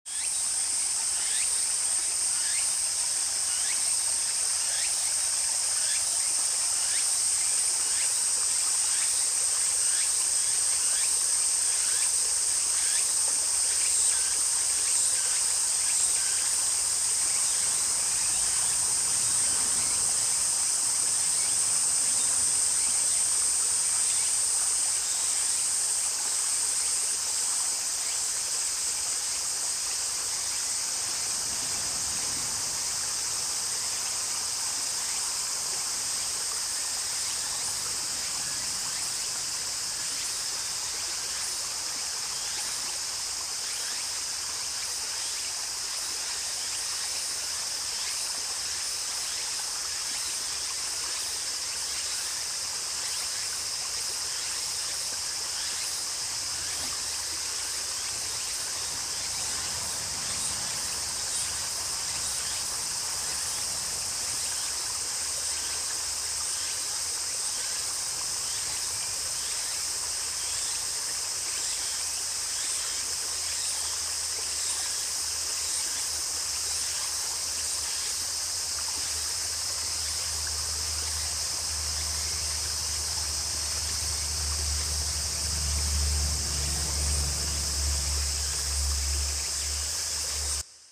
【環境音】″𓆦″ ꒰ コラボ歓迎 ꒱ / 蝉